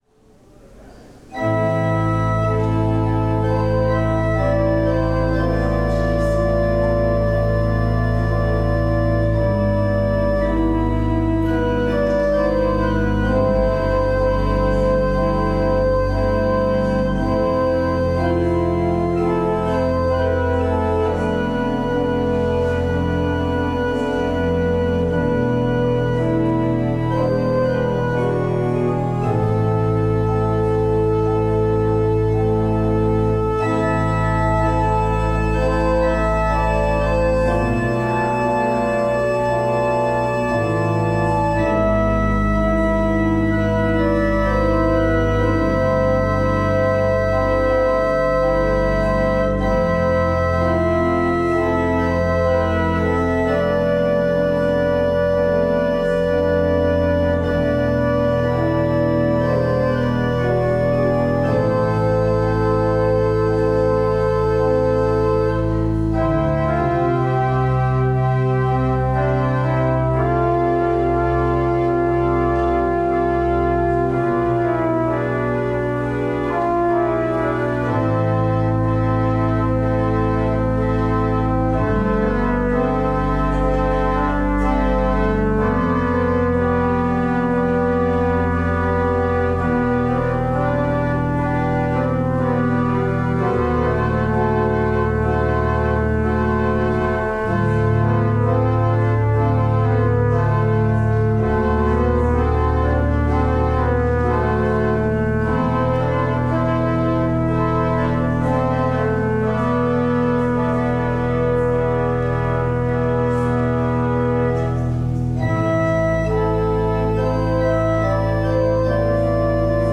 Service of Worship
Prelude